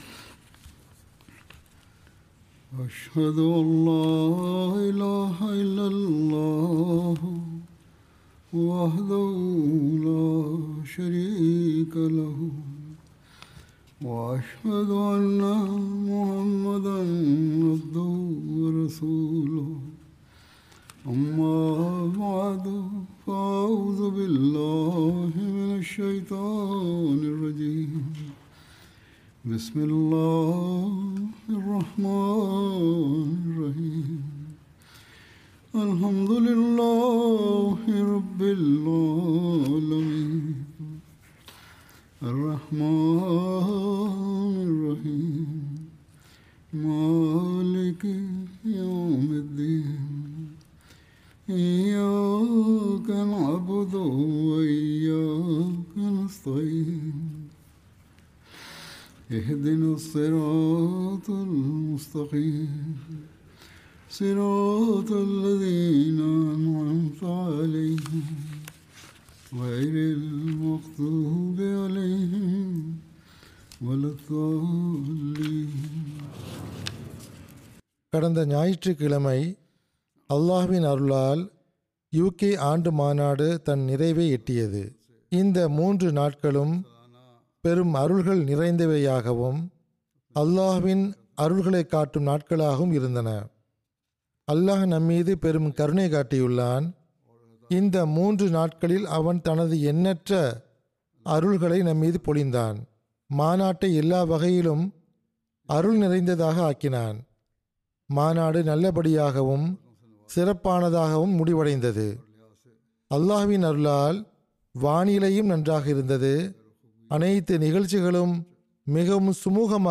Tamil Translation of Friday Sermon delivered by Khalifatul Masih